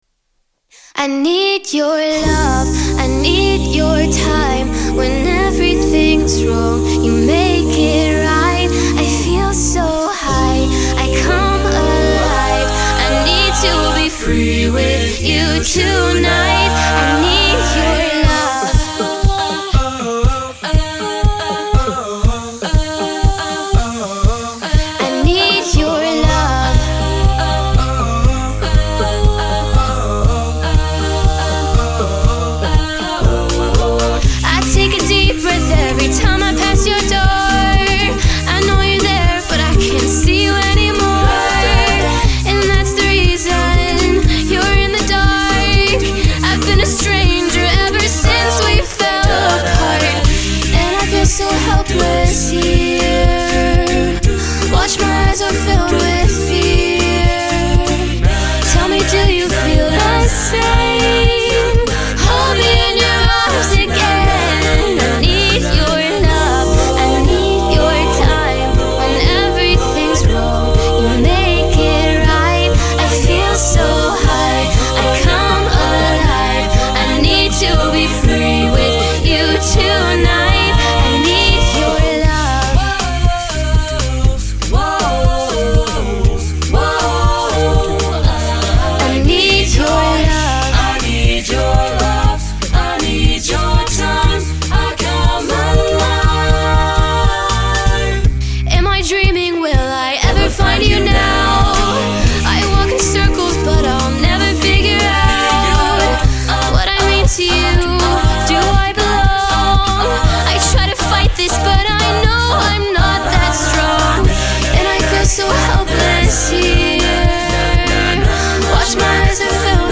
choral
SATB divisi, a cappella (SATB recording), sample